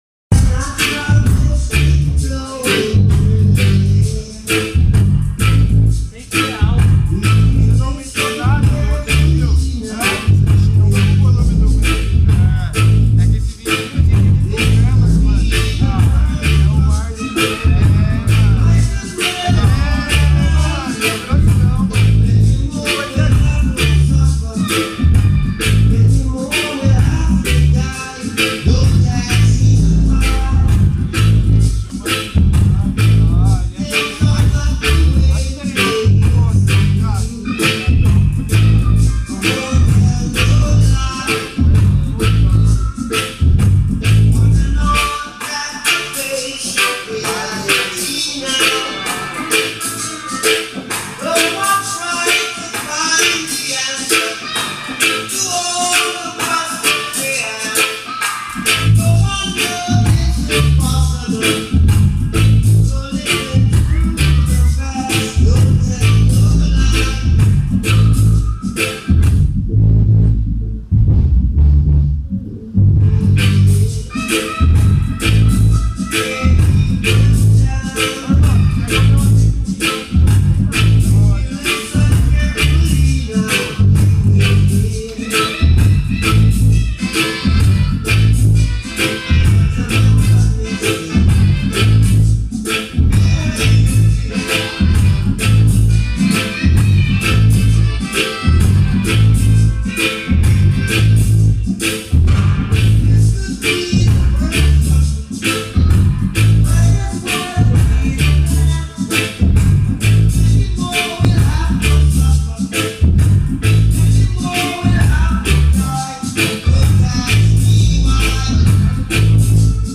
A massive selection inna roots style
conscius & militant vibes from Brasil!
São Paulo / Brazil